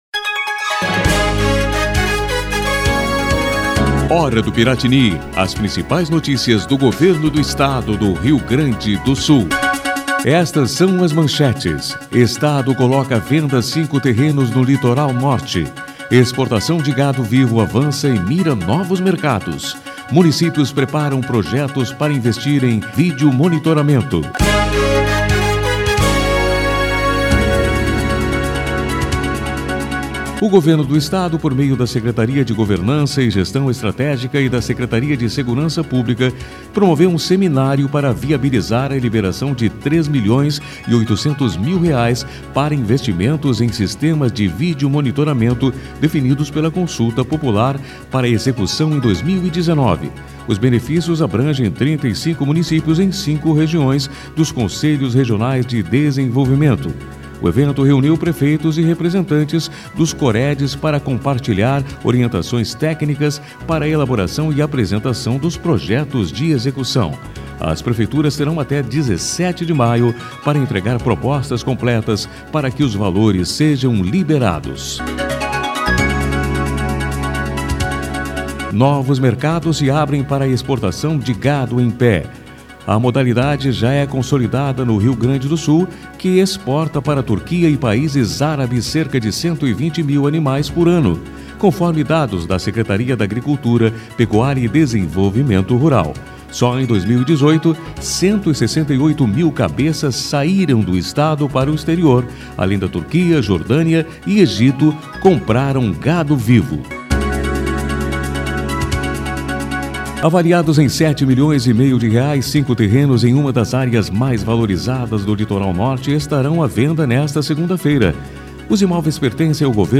A Hora do Piratini é uma síntese de notícias do Governo do Estado, produzida pela Secretaria de Comunicação.